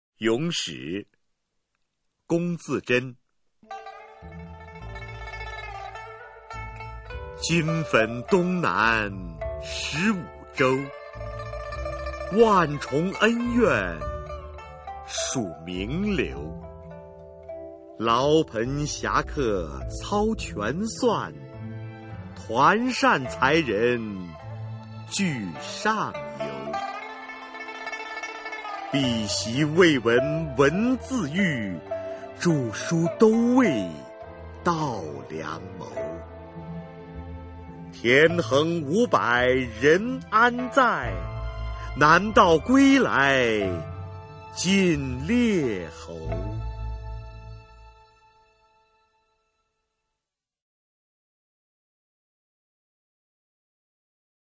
[清代诗词诵读]龚自珍-咏史 配乐诗朗诵